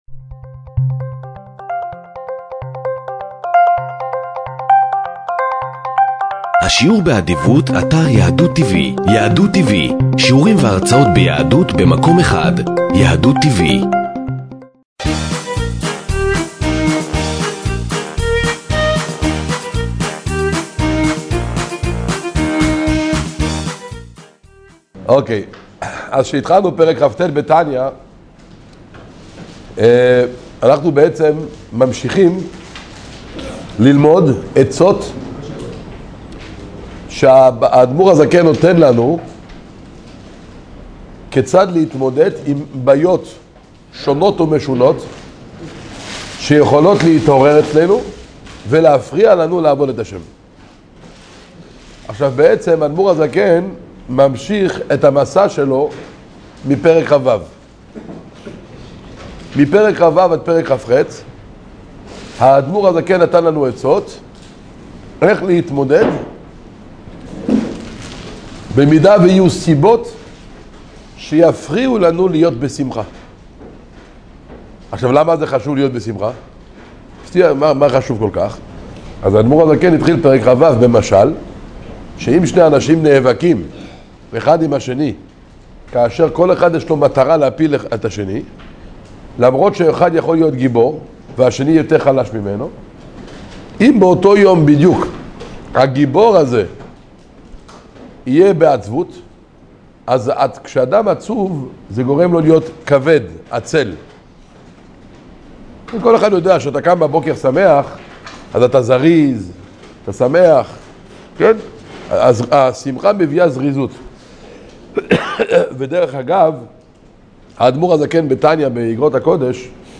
שיעור תניא